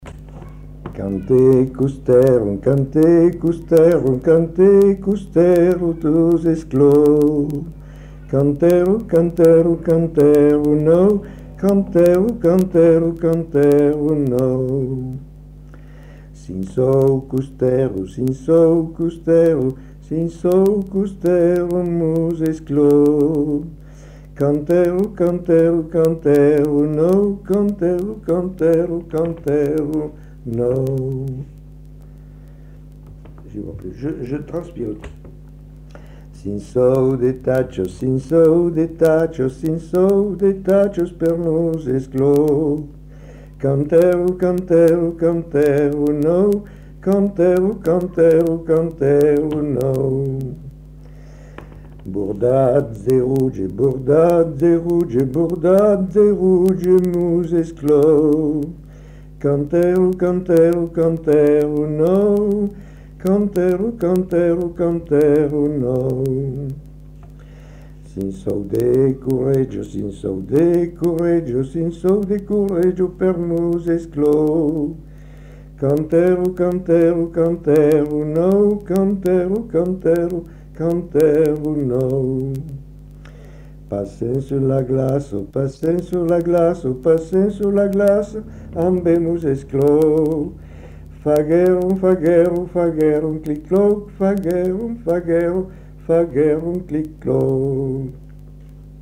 Lieu : Lodève
Genre : chant
Effectif : 1
Type de voix : voix d'homme
Production du son : chanté
Classification : danses